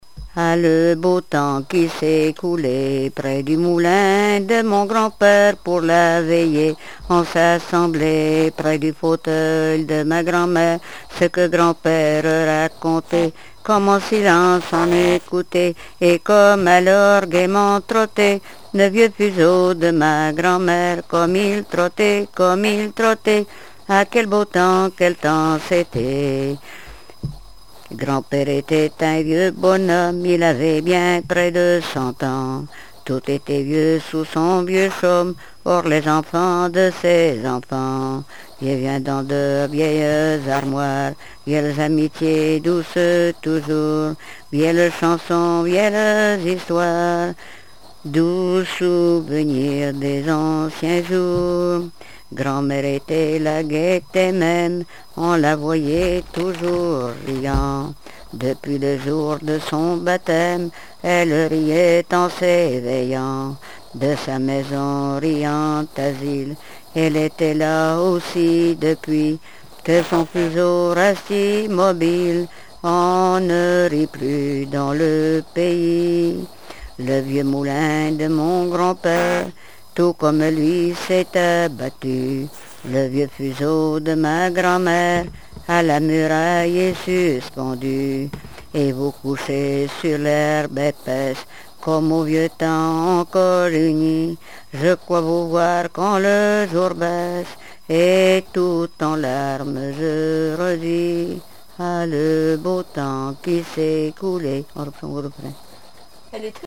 Répertoire de chansons traditionnelles et populaires
Pièce musicale inédite